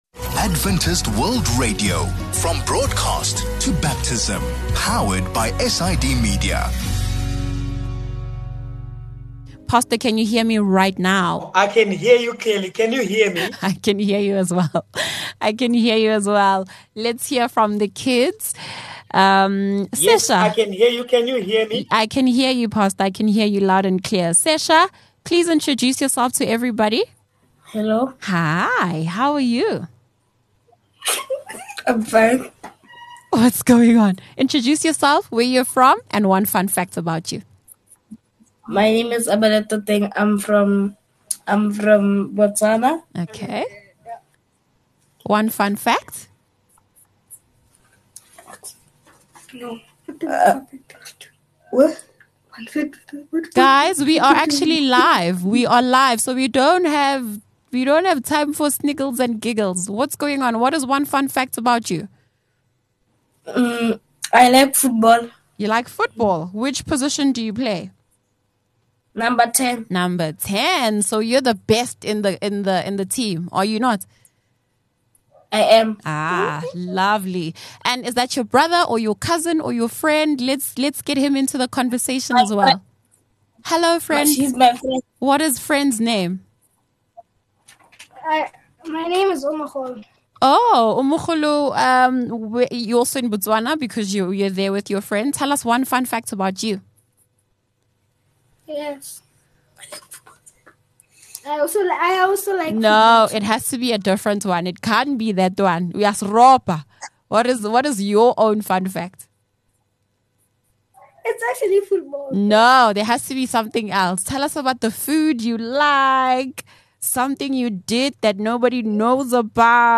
On this platform our young ones come on air and ask their Bible questions, and boy do they ask!